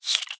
sounds / mob / silverfish / say3.ogg